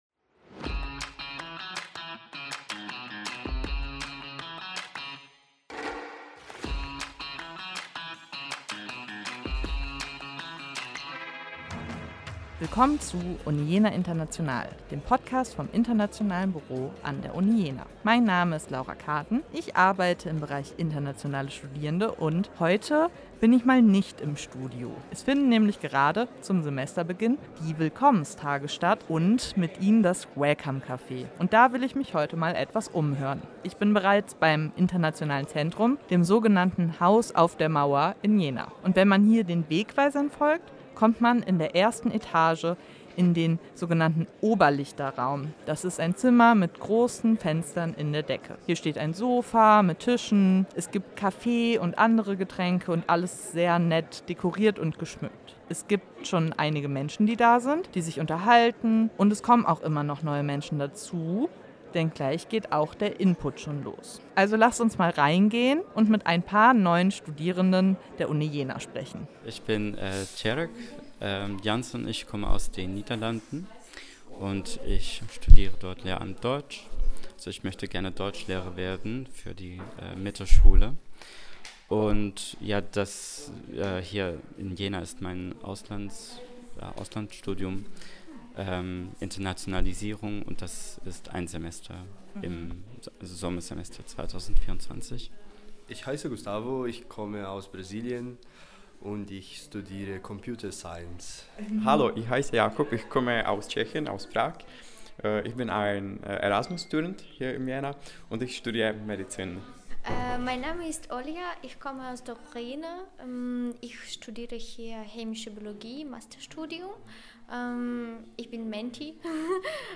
Wir nehmen euch mit zum WelcomeCafé im Internationalen Centrum Jenas. Dort lernen wir einige neue Studierende kennen und erfahren mehr über dieses Angebot zum Studienstart.